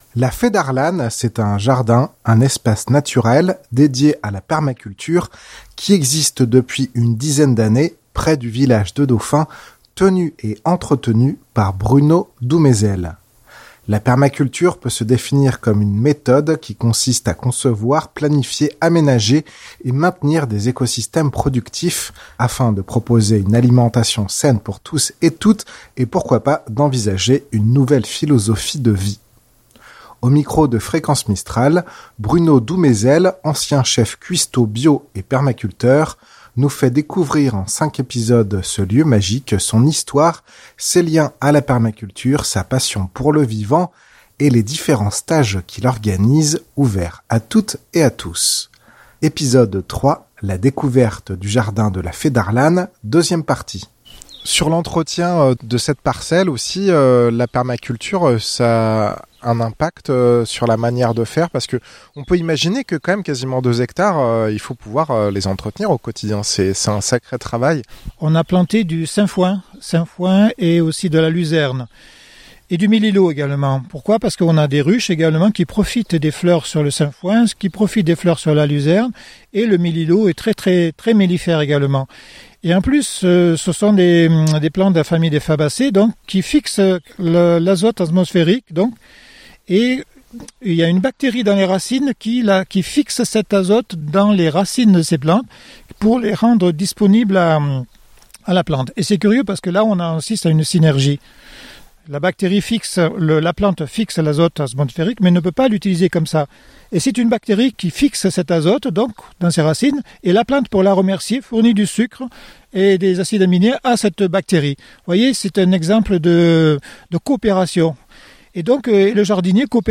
Épisode 3: la découverte du jardin 2ème partie Reportage